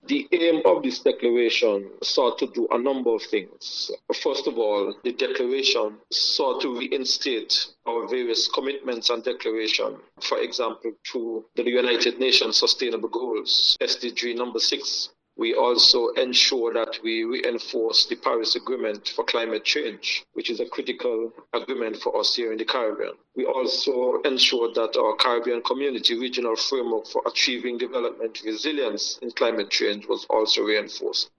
Mr. Brand, along with other Caribbean countries’ leaders signed the Water Declaration of Port of Spain. He shared more details on the initiative with the VONNEWSLINE.
Mr. Brand spoke about how developed Nevis’ water systems are and the steps forward for further development: